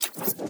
fnl/Assets/Extensions/Advanced_UI/Alerts_Notifications/Cybernetic/Cybernetic Technology Affirmation 10.wav at master
Cybernetic Technology Affirmation 10.wav